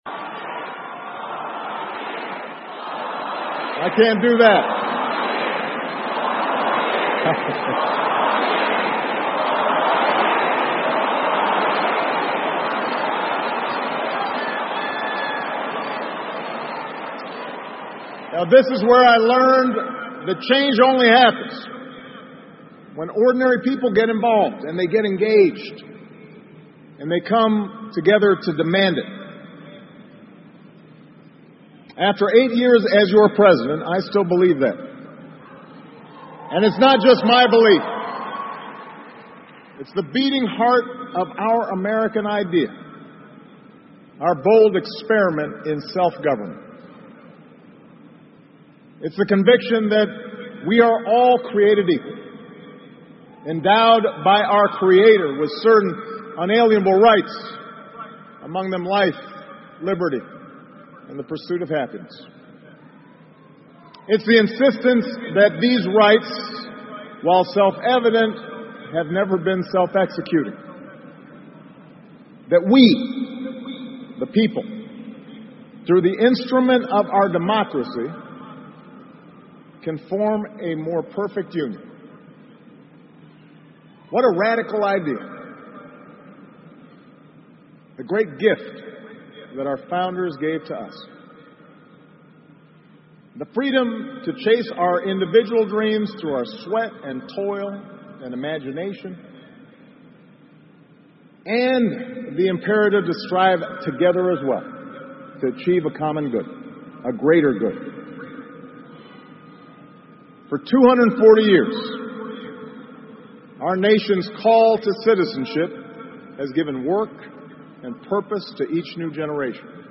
奥巴马每周电视讲话：美国总统奥巴马告别演讲(1) 听力文件下载—在线英语听力室